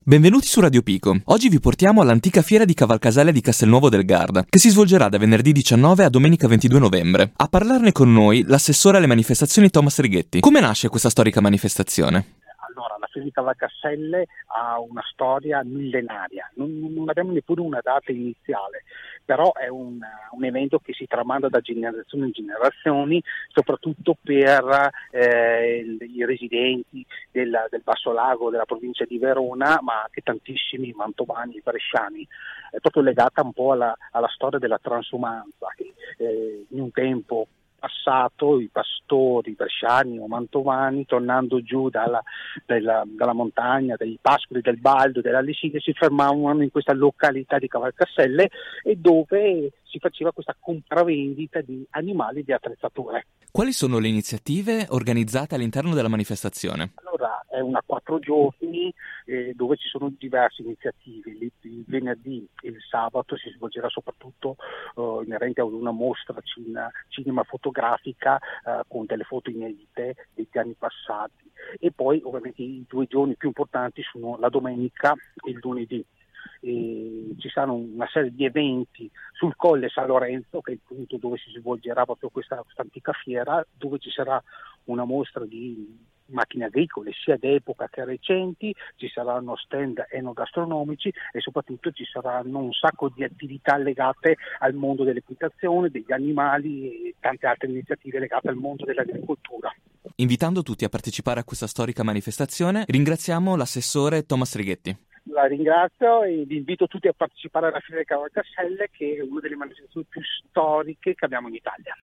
Ai nostri microfoni Thomas Righetti, assessore alle manifestazioni di Castelnuovo del Garda: